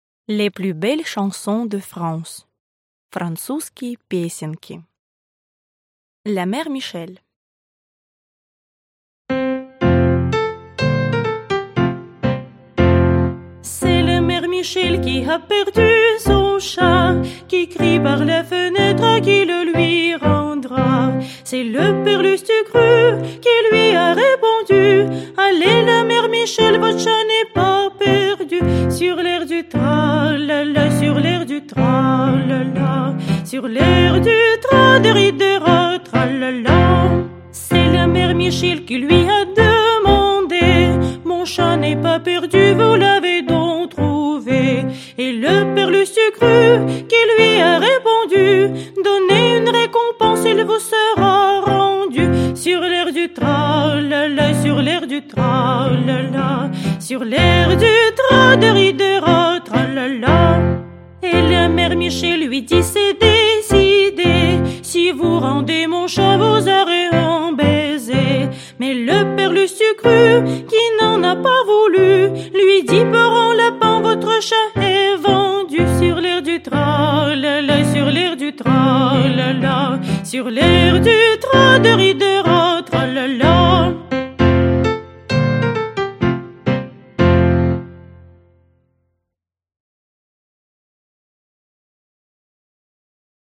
Аудиокнига Французские песенки.